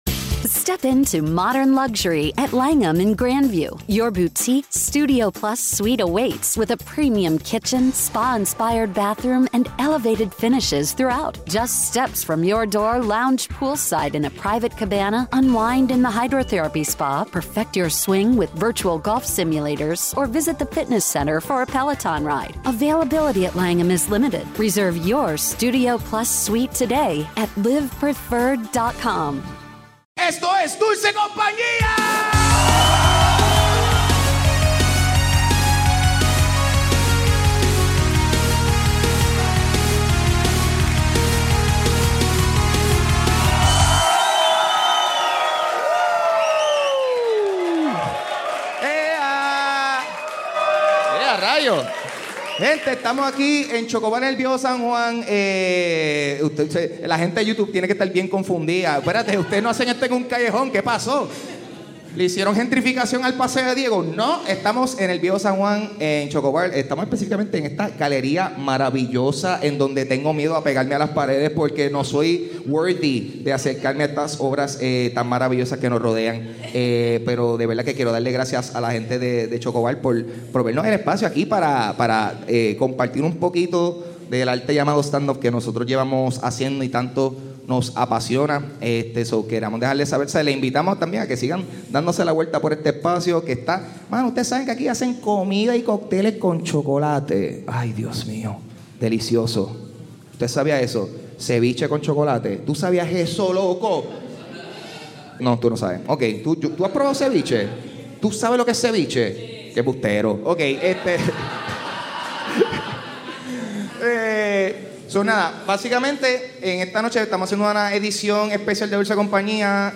Live en Chocobar | Noviembre 1, 2024